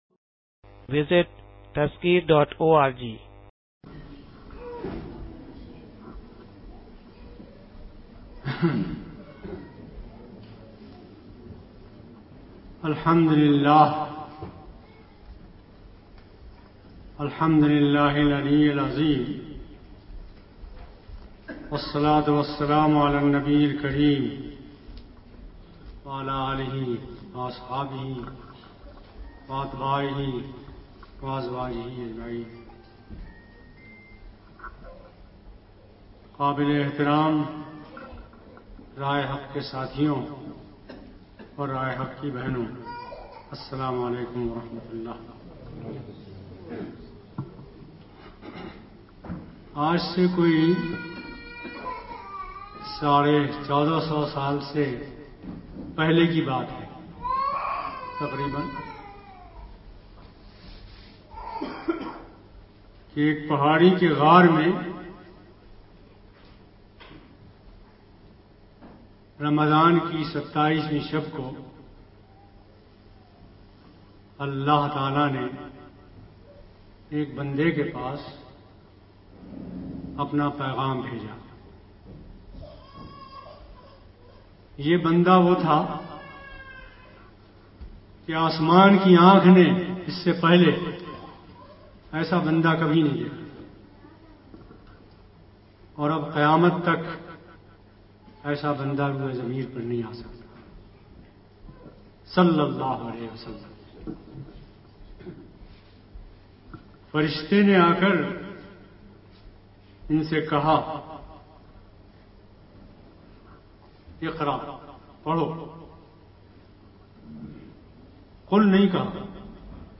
This lecture is delivered at Islamic Forum for Australian Muslims